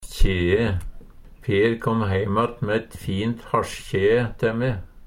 Høyr på uttala Ordklasse: Substantiv inkjekjønn Kategori: Personleg utstyr, klede, sko Attende til søk